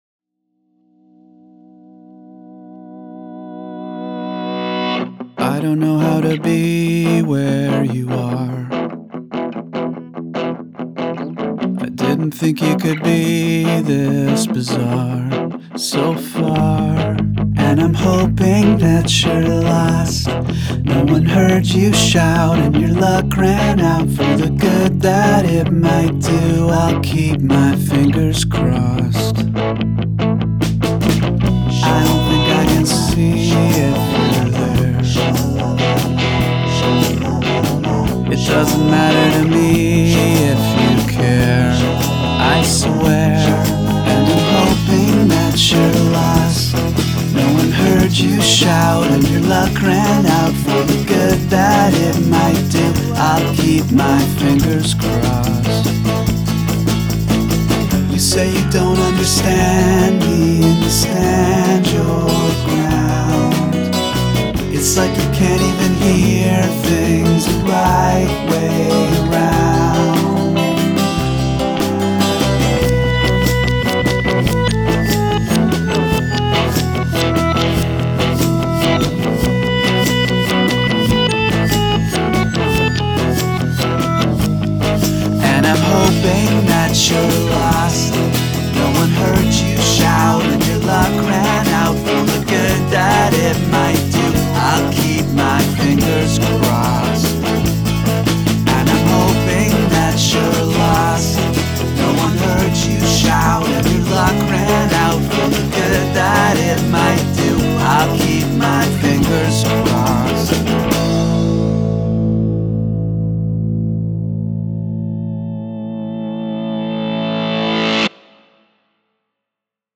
include a section of backwards singing or instrumentation